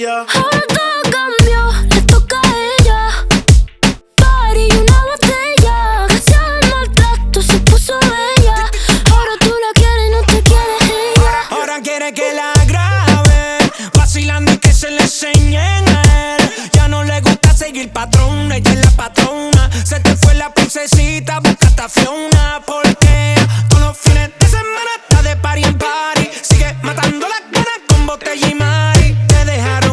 Genre: Latino